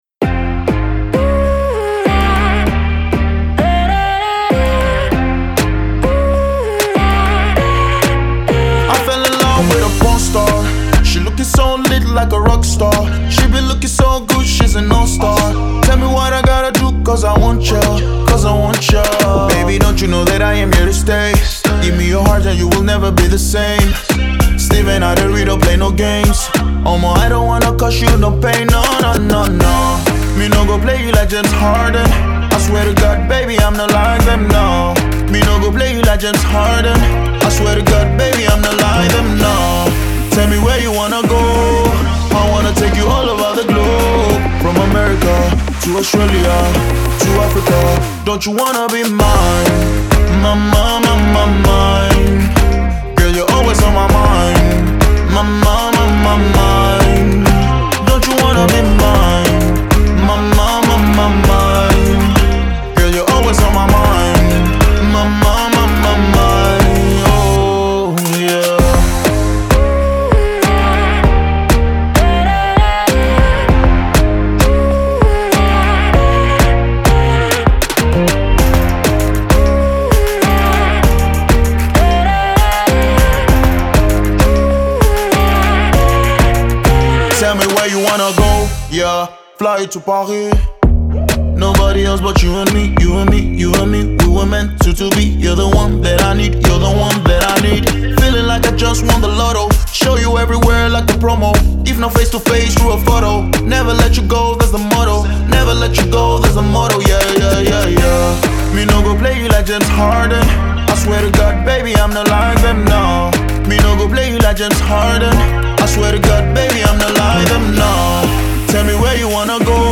это энергичная танцевальная композиция в жанре хаус